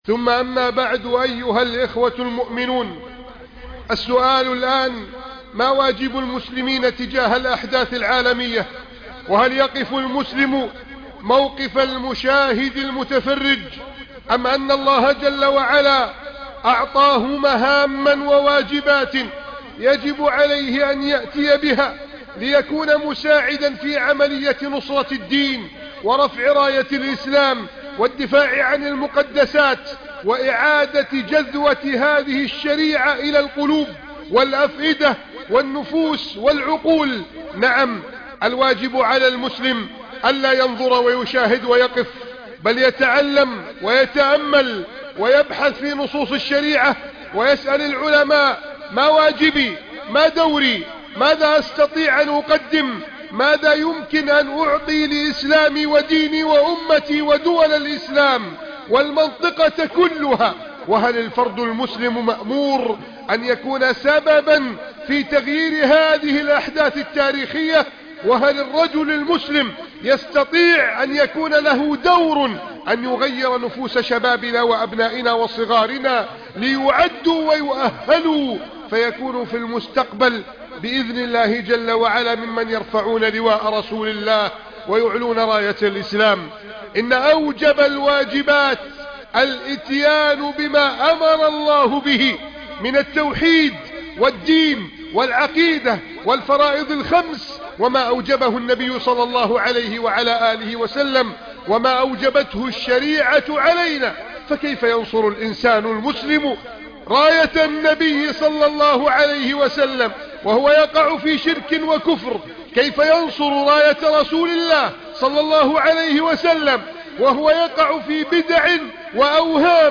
ذاهب إلى دمشق ! أحدث و أقوى خطبة جمعة